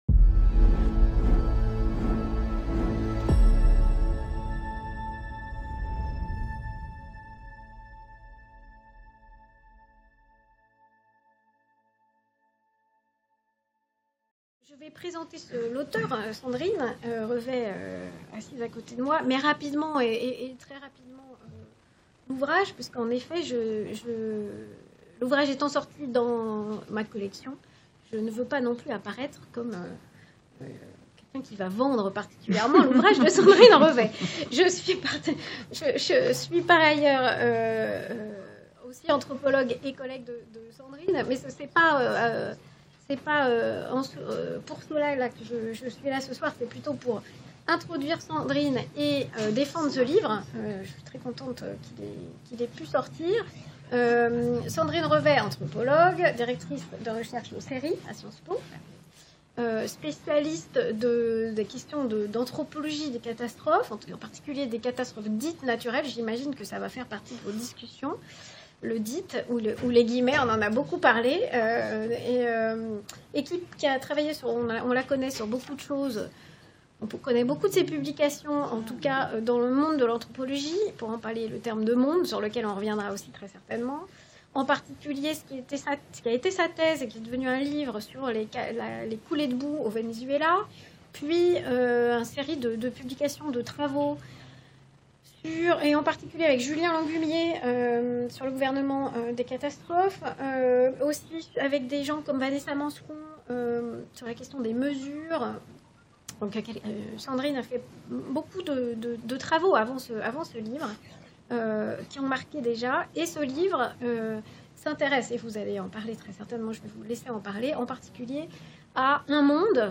Entretien du Forum - Mardi 27 novembre 2018 Les Entretiens organisés au forum ont pour vocation d’exposer au plus grand nombre les résultats de la recherche en SHS une fois ceux-ci publiés, quel qu’en soit le support (film, working paper, livre, article papier ou numérique, etc.).
Le ton est plus libre.
De fait, ils laissent la part belle au public, à l’interaction public-chercheurs, aux débats, au feuilletage des livres…